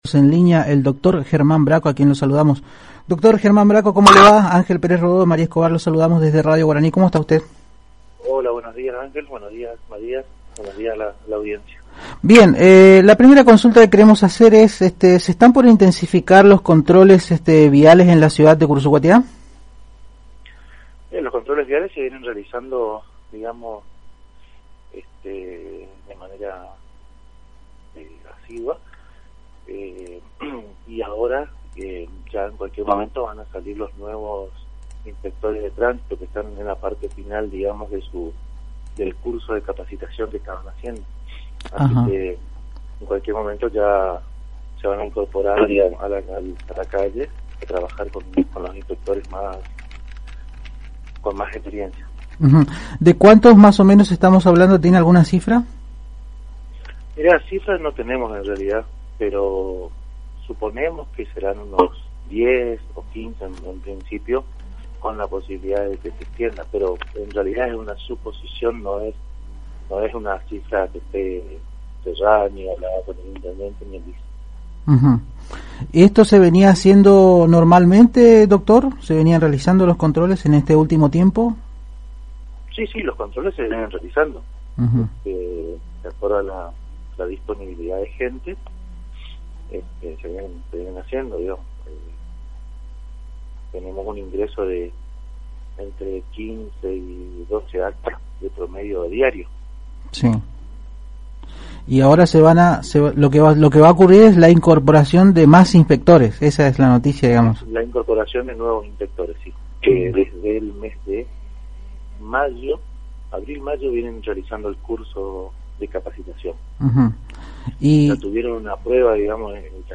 german_bracco_juez.mp3